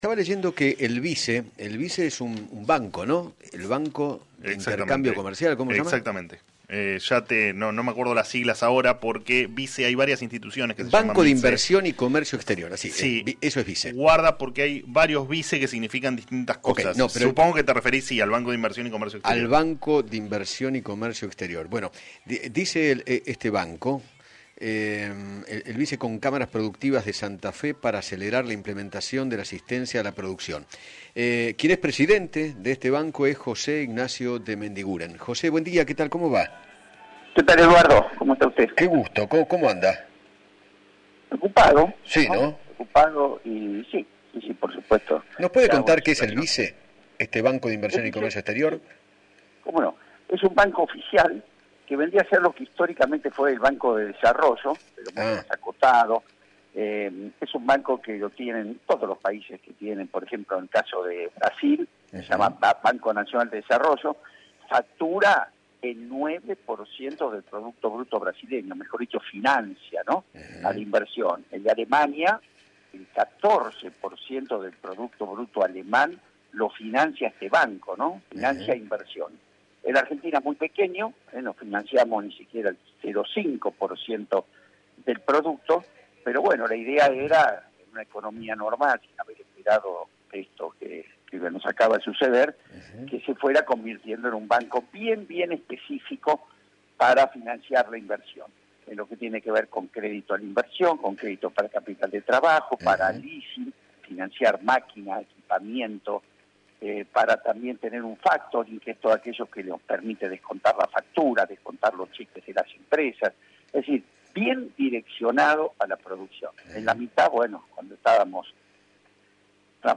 José Ignacio De Mendiguren, presidente del Banco de Inversión y Comercio Exterior se Argentina, dialogó con Eduardo Feinmann sobre el proyecto que ofrece BICE para que las pymes accedan al Fondo de Garantías que permite gestionar créditos ante los bancos.